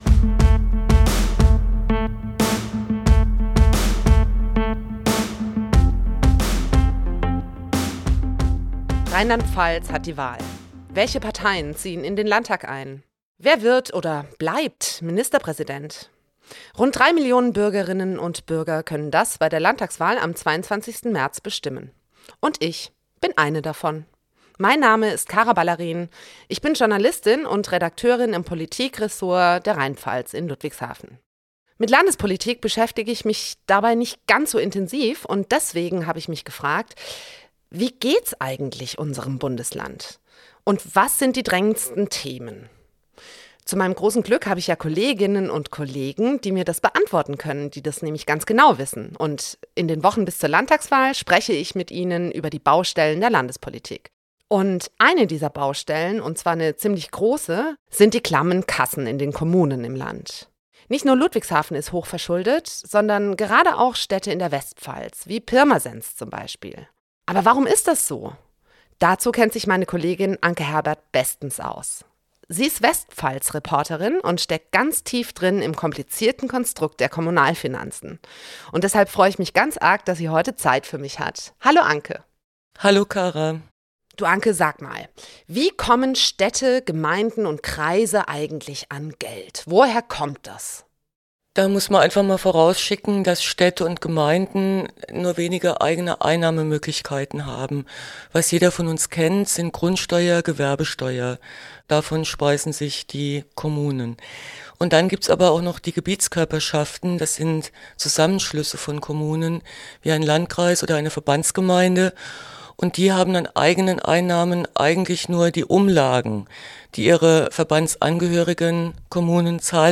In der zweiten Folge dreht sich alles ums Geld – vor allem um die Frage, ob die Kommunen im Land ausreichend finanziert sind, damit die Bürgerinnen und Bürger vor Ort gut leben können. Dafür spricht Politikredakteurin